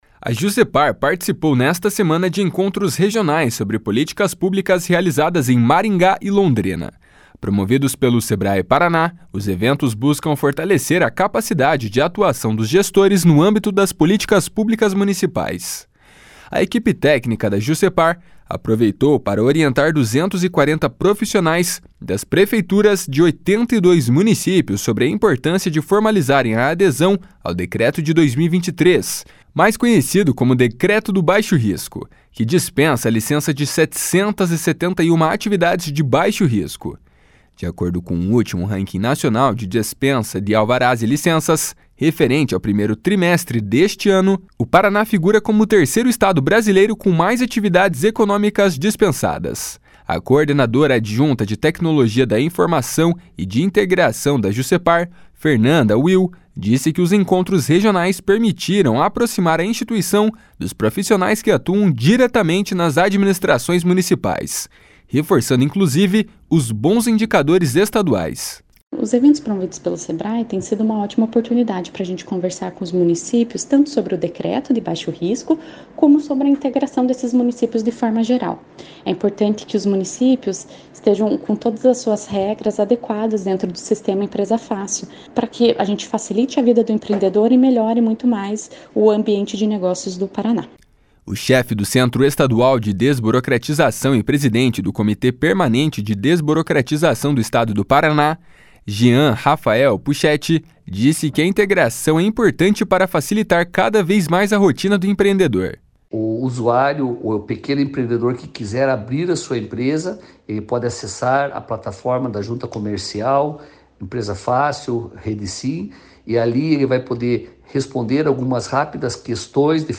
O chefe do Centro Estadual de Desburocratização e presidente do Comitê Permanente de Desburocratização do Estado do Paraná, Jean Rafael Puchetti, disse que a integração é importante para facilitar cada vez mais a rotina do empreendedor. // SONORA JEAN RAFAEL PUCHETTI //